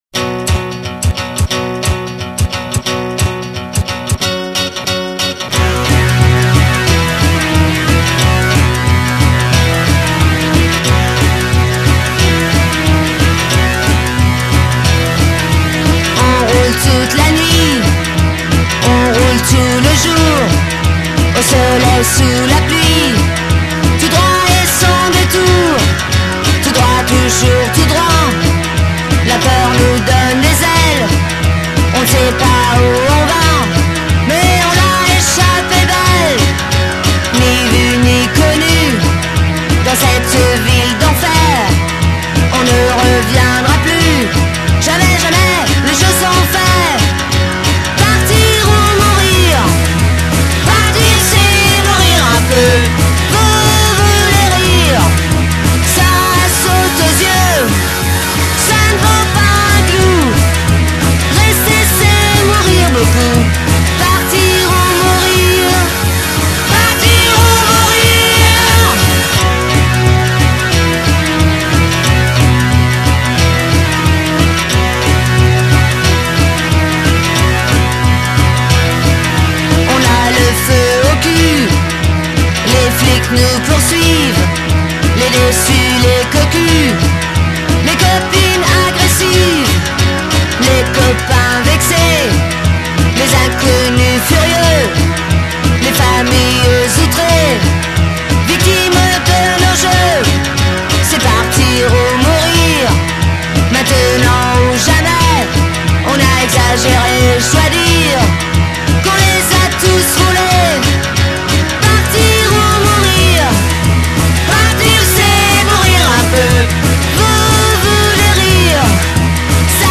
But most of all, it's just good old-fashioned Germpop.